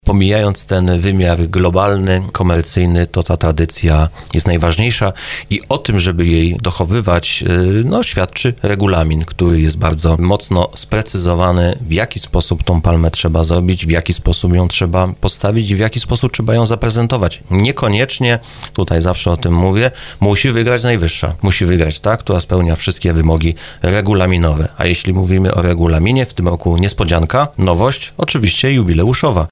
Wójt Lipnicy Murowanej Tomasz Gromala przyznaje, że w czasie finału konkursu da się odczuć zdrową rywalizację.